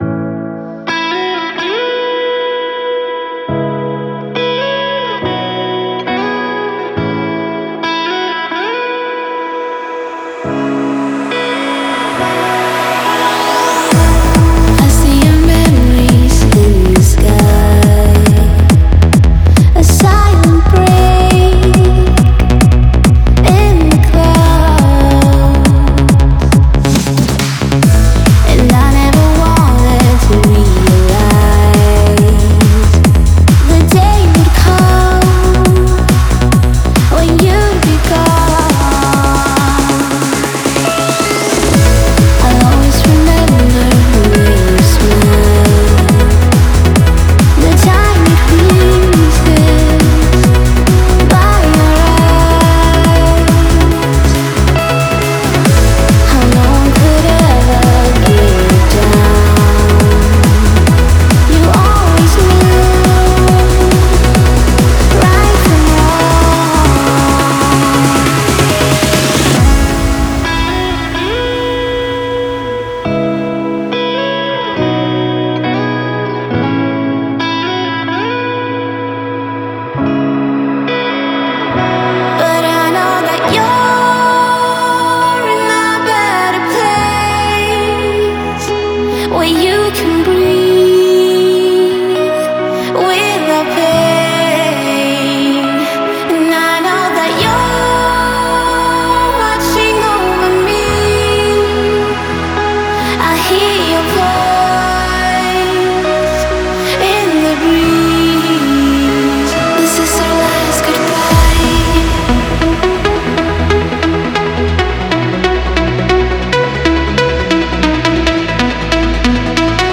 мощный трек в жанре транс